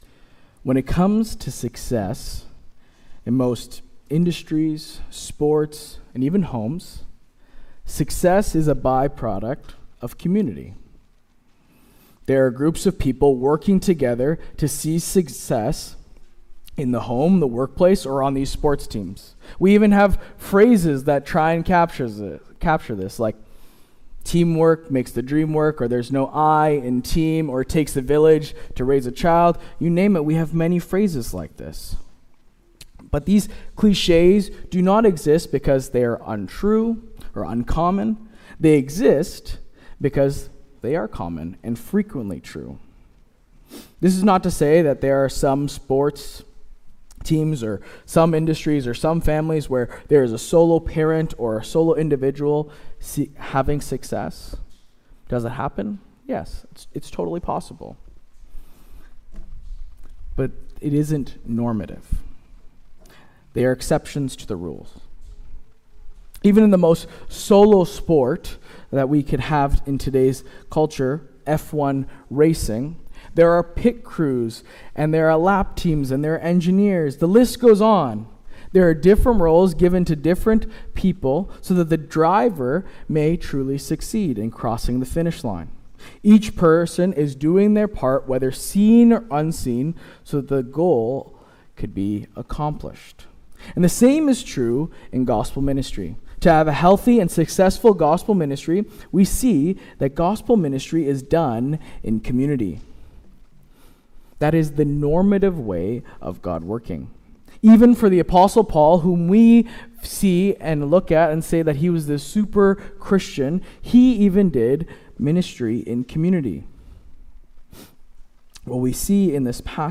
Sermon “The Community Project